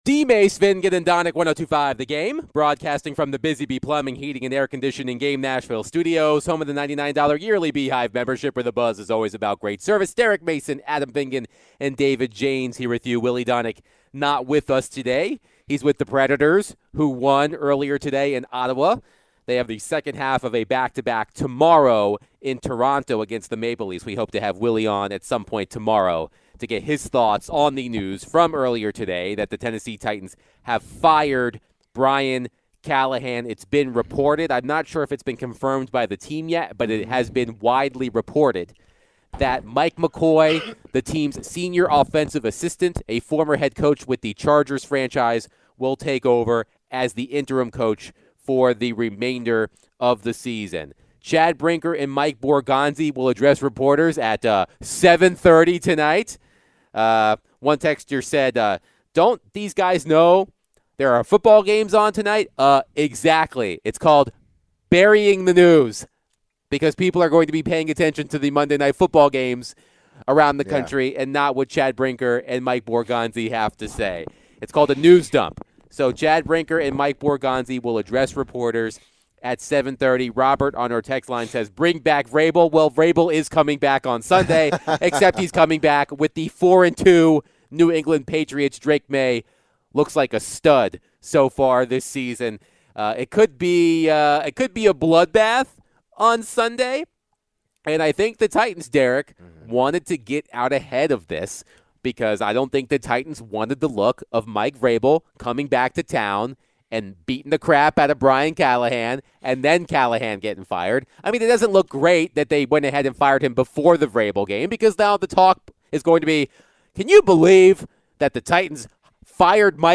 They also took calls and text on this discussion and more